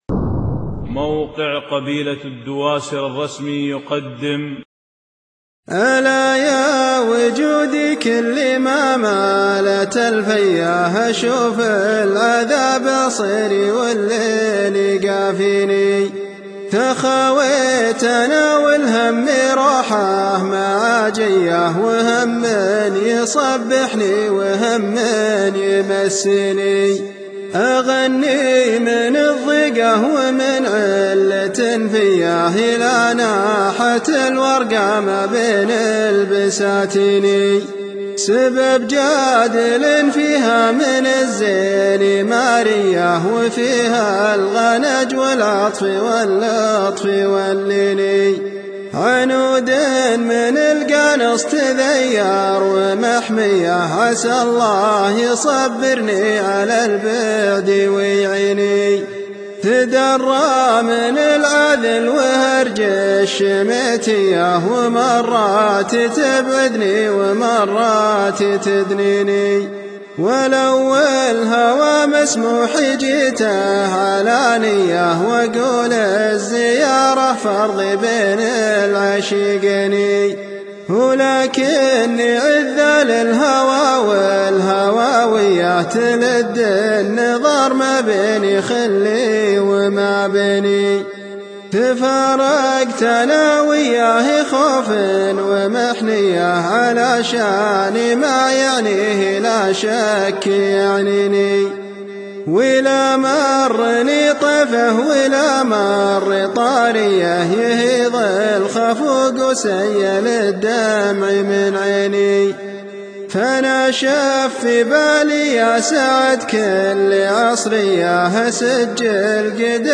قصائد صوتيّة حصريّة للموقع